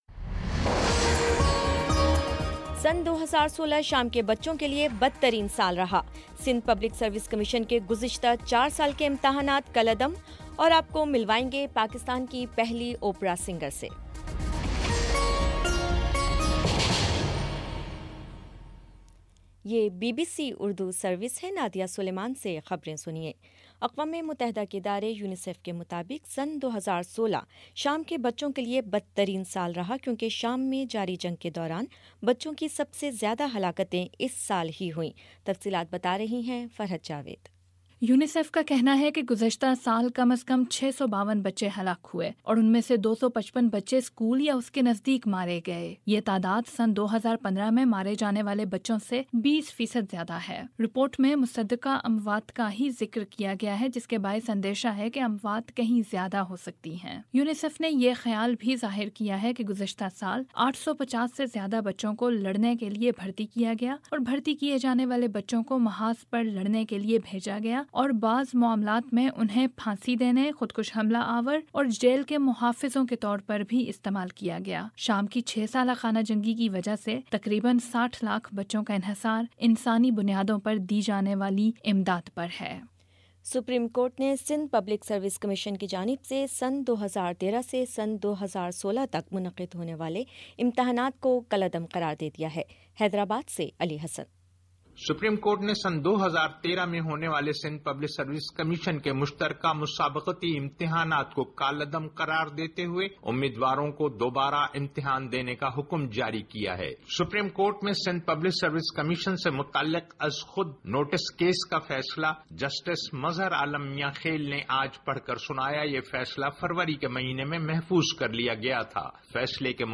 مارچ 13 : شام چھ بجے کا نیوز بُلیٹن